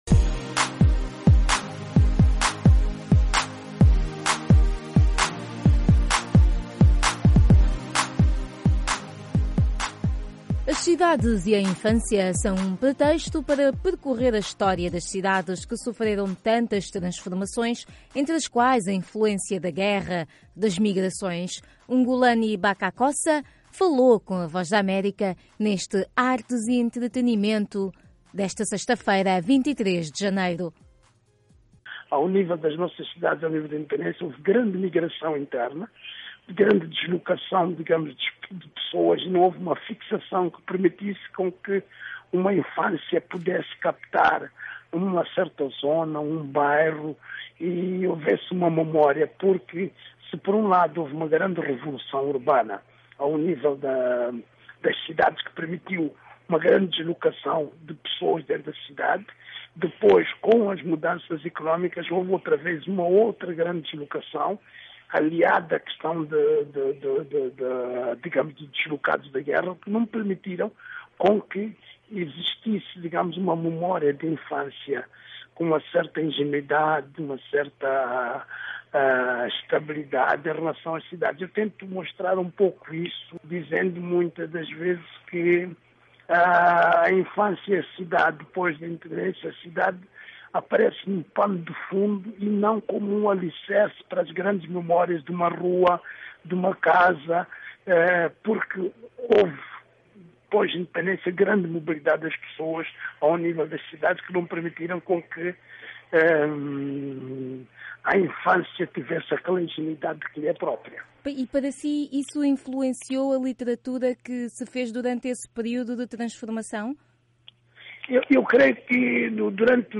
O escritor moçambicano defende a recuperação do imaginário inocente da infância na literatura infanto-juvenil, numa conversa em que referiu o alto nível de literacia dos países africanos de língua portuguesa e a necessidade de se ultrapassar a obsessão contra o Acordo Ortográfico